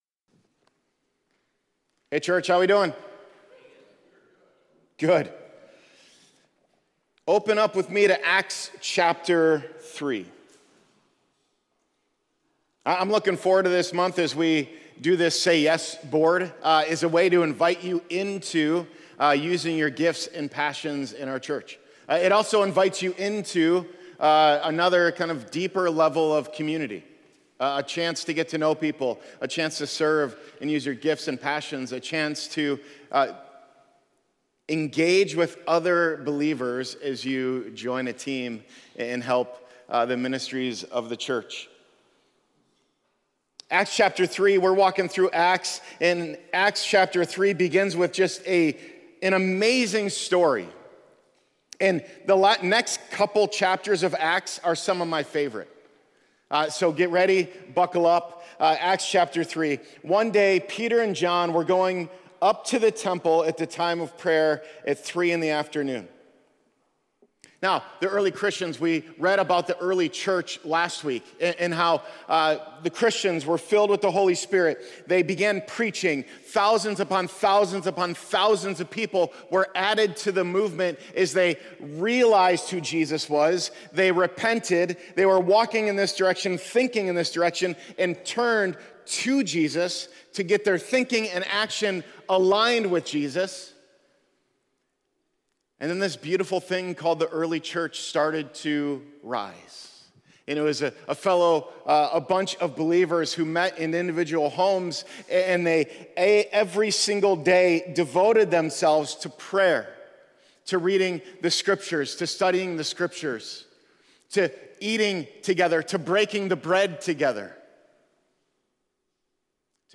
Religion Sunday Service Renew Church Christianity Content provided by Renew Church.